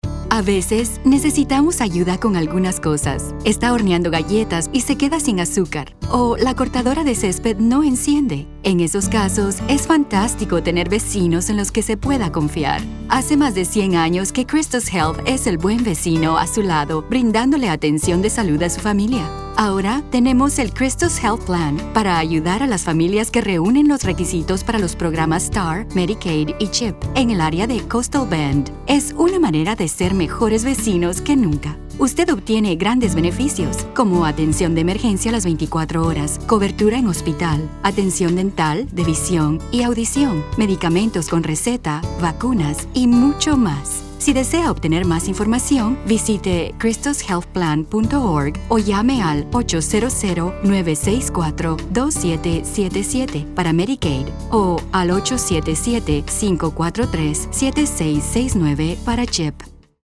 Neutral Latin American Spanish female voice.
Sprechprobe: Werbung (Muttersprache):